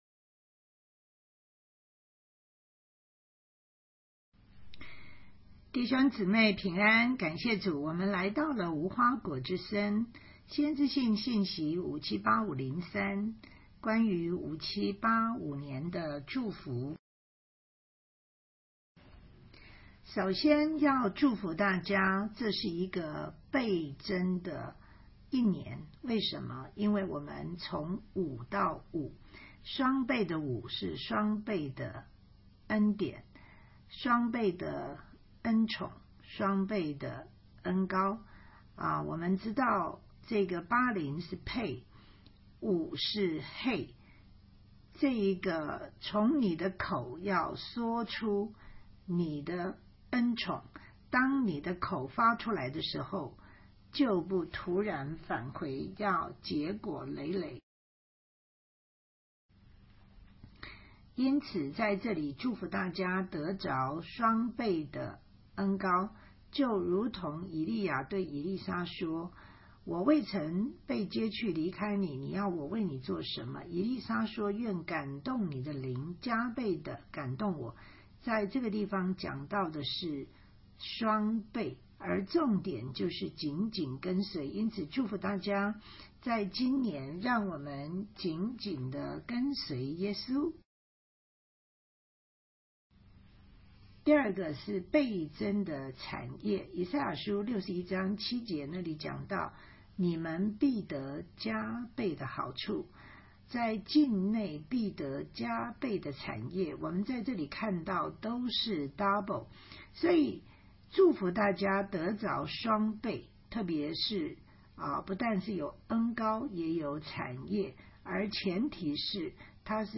主题教导 每周妥拉